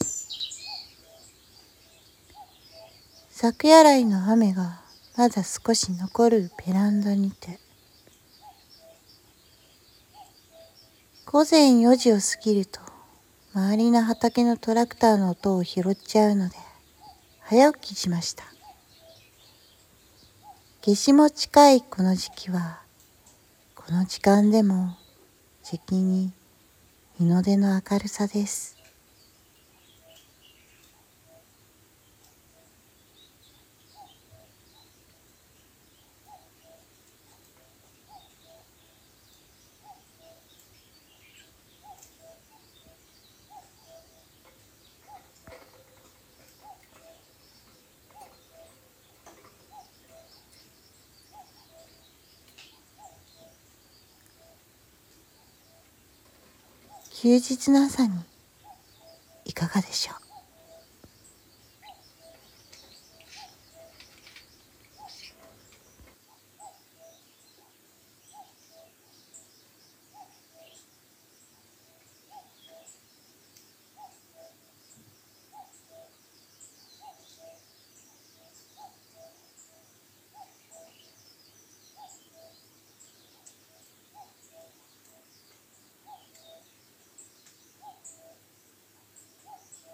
】【環境音】カッコウの啼き声 午前3時半 nanaRepeat